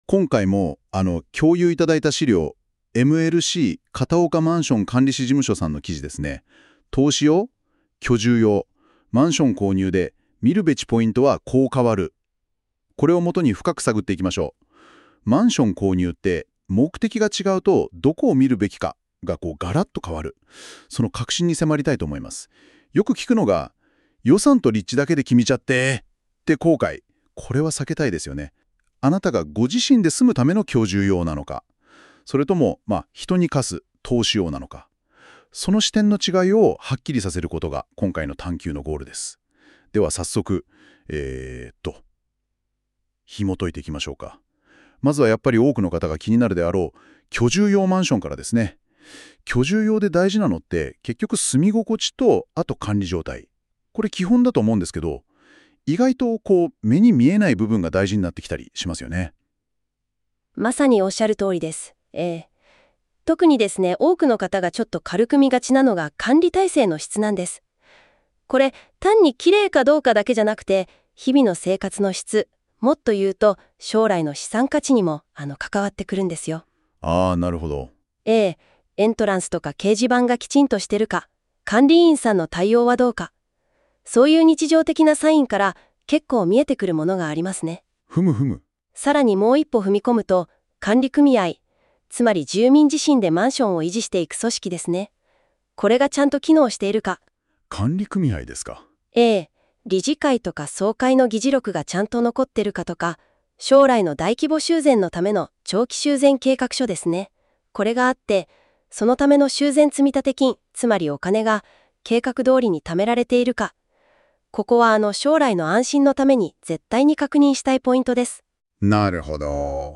🎧 音声解説（約7分） 👂 耳で理解したい方は、対話式の音声解説をこちらで再生できます。
音声解説）投資用？居住用？マンション購入で見るべきポイントはこう変わる.mp3